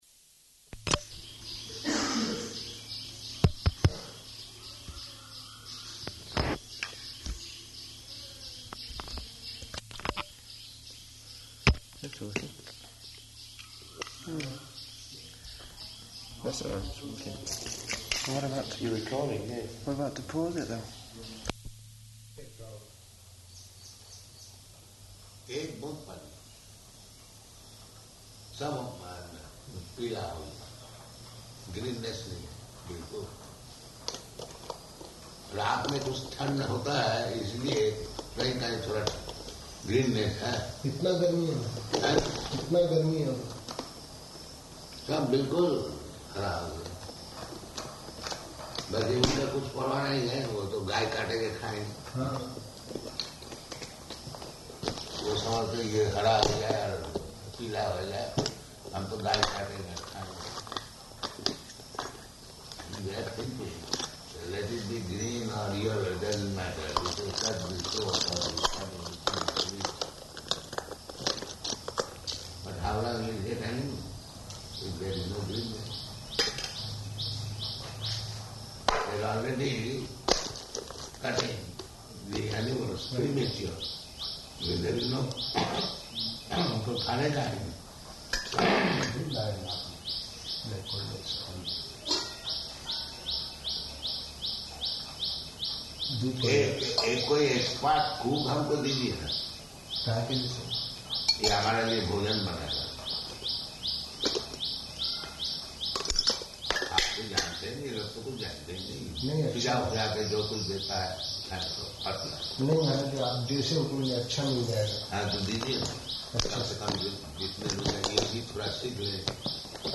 Room Conversation
Location: Hyderabad